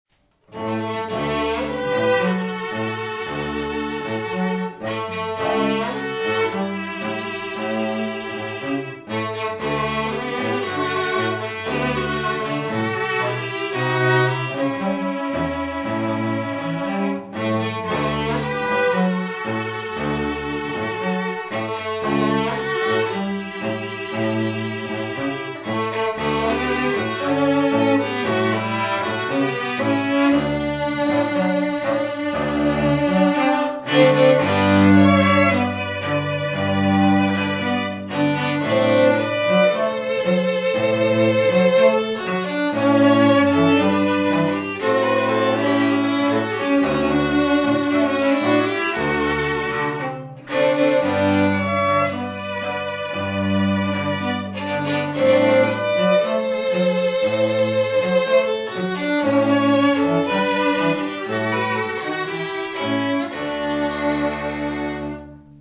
STRING TRIO SAMPLES